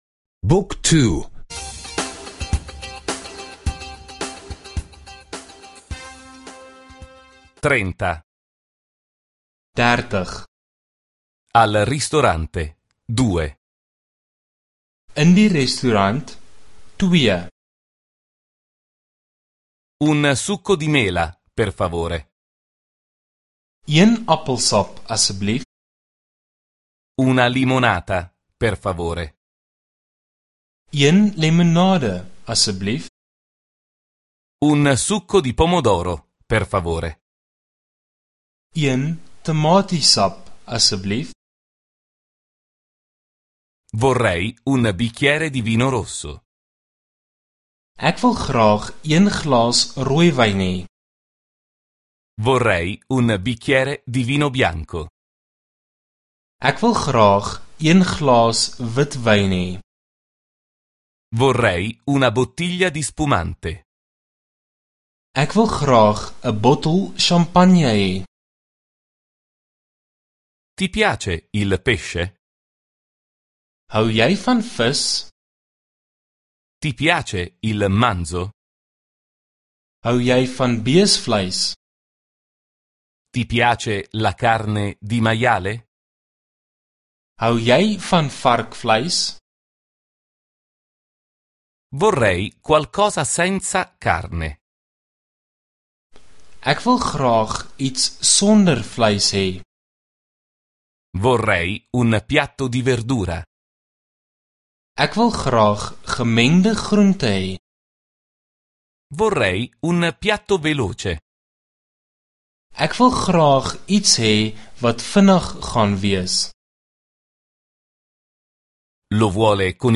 Audio corso afrikaans — ascolta online